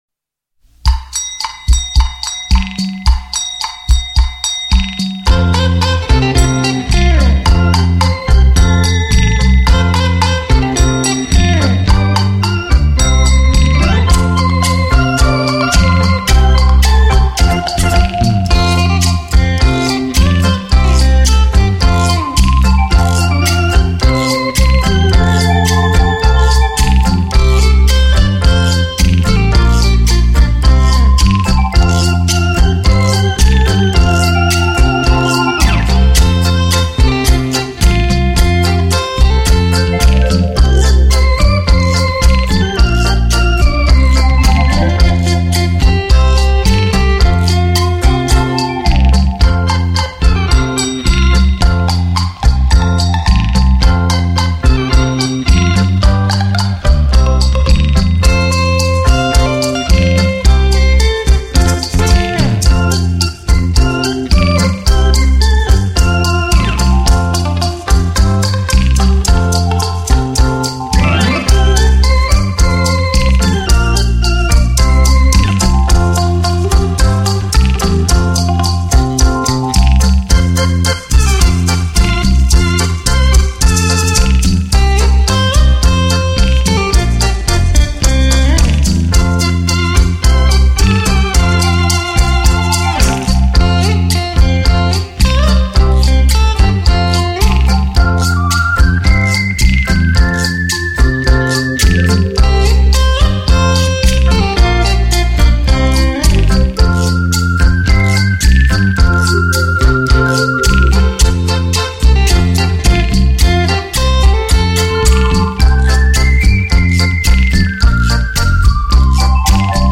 专辑格式：DTS-CD-5.1声道
360度声音动态 超立体环绕音场
近百种真实自然声音．最佳环境音响测试片